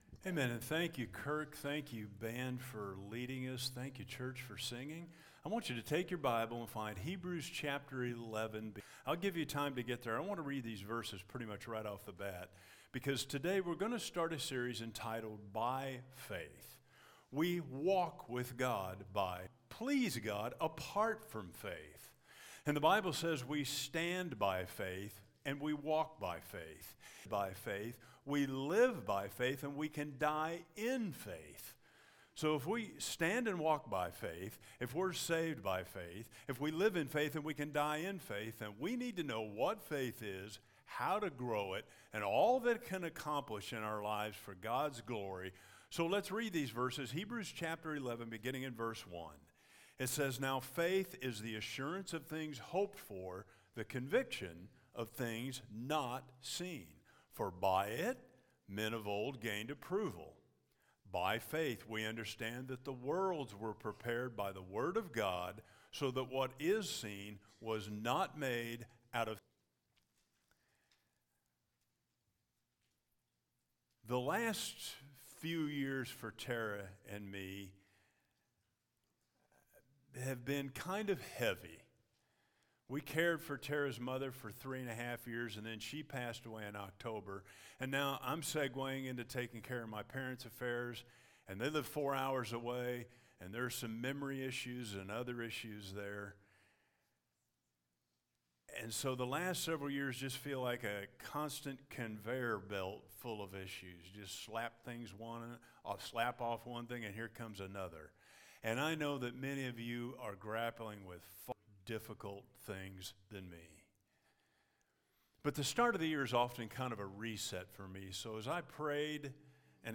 Faith Is - By Faith Sunday